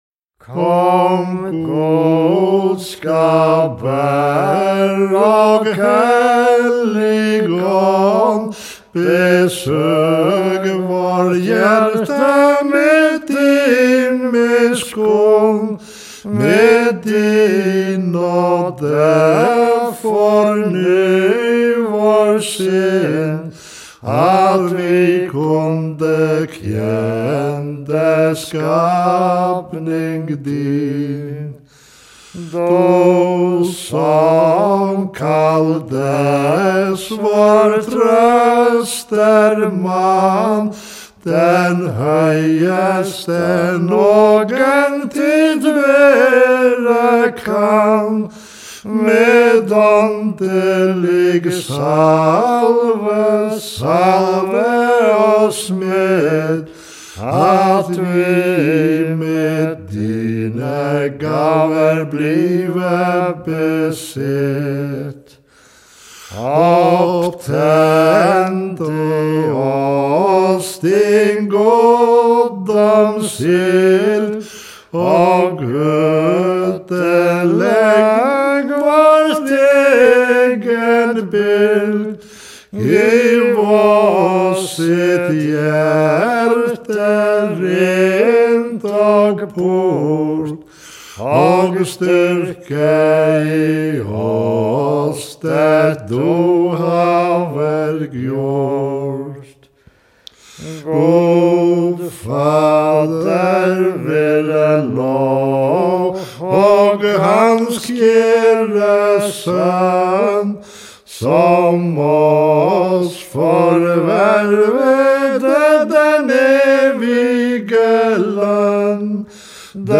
Kingosálmar